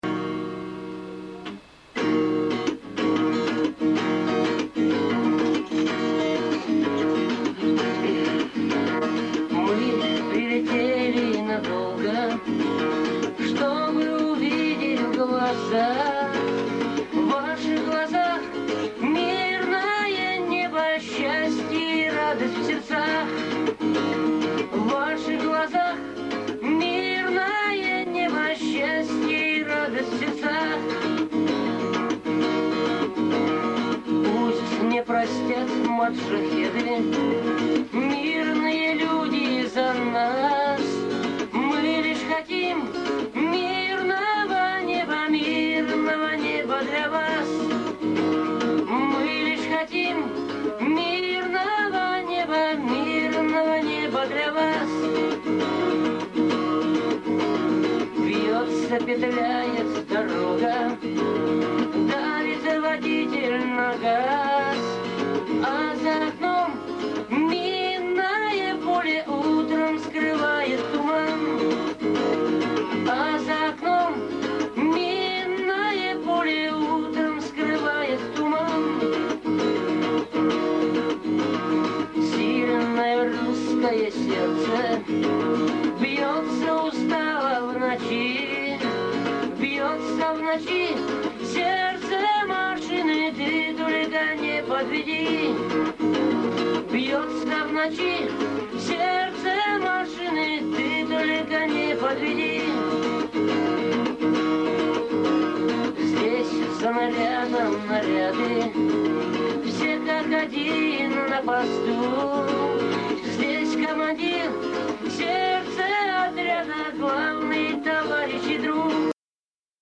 Армейские под гитару